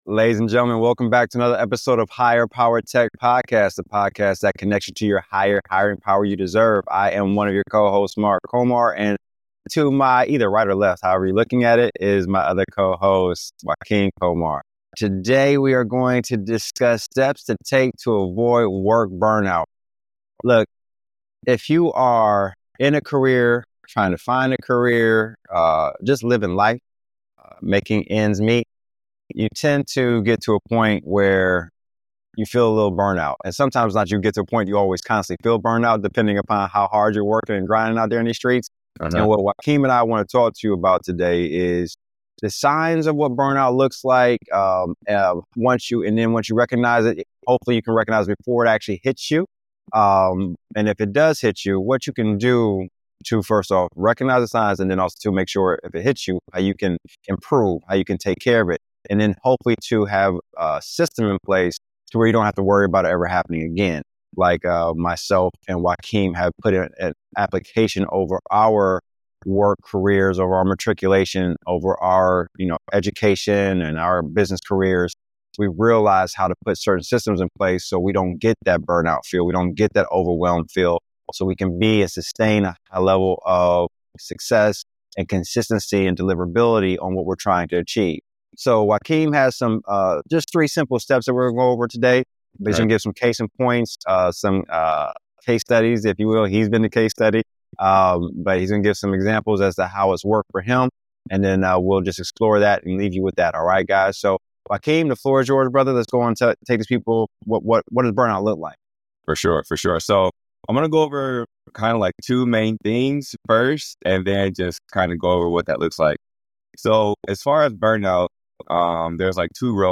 They emphasize the importance of mindfulness and meditation in recognizing emotional and physical exhaustion, and share practical techniques to maintain a sustainable work-life balance. The conversation highlights the necessity of self-care, efficient time management, and the little things that can significantly impact personal and professional success.